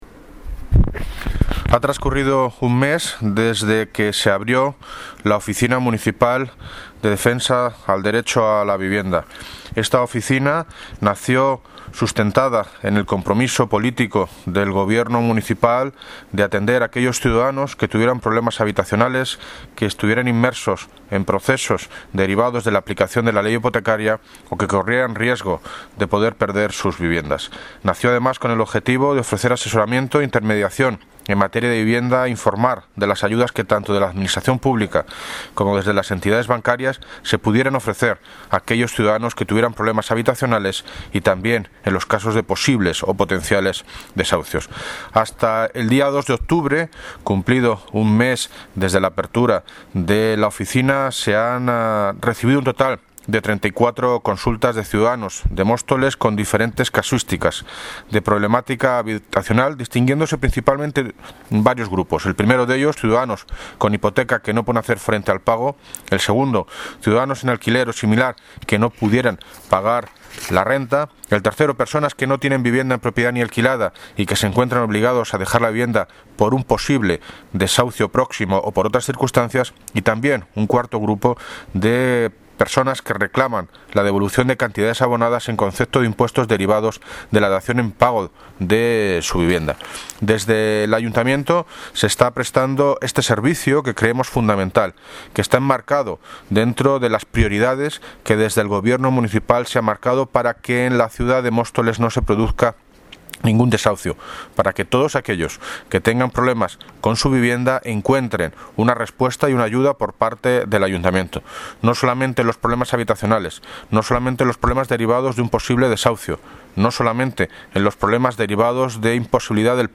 Audio - David Lucas (Alcalde de Móstoles) sobre oficina antidesahucios